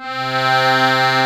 C3 ACCORDI-L.wav